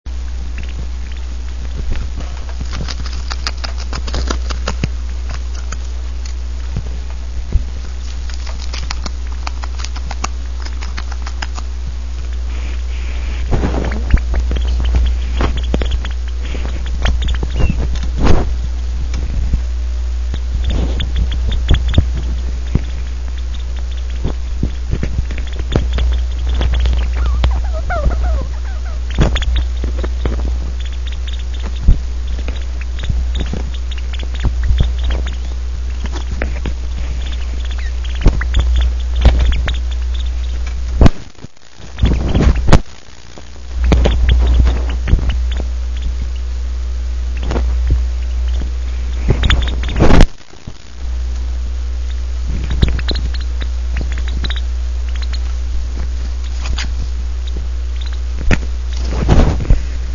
Sounds degus make
My youngest degu in a talkative mood.